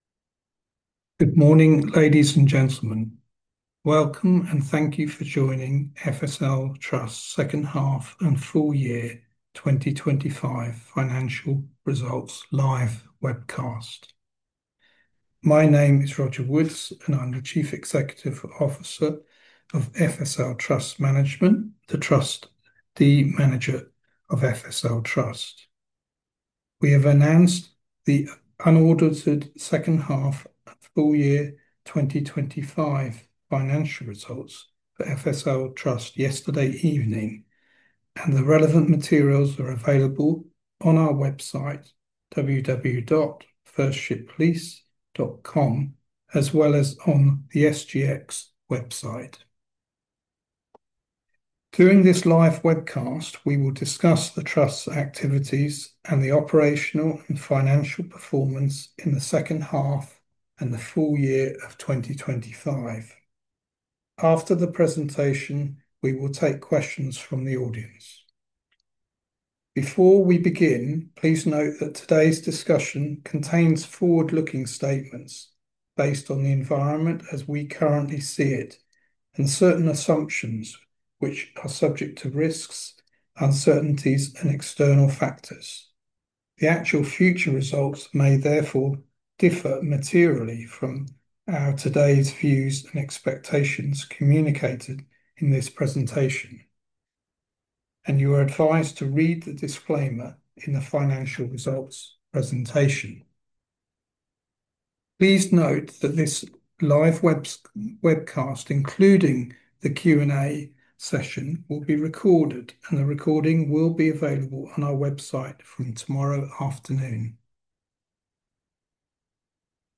Audio Webcast - Management Presentation (3980 KB)
2H_FY2025_Audio_Webcast-Management_Presentation.mp3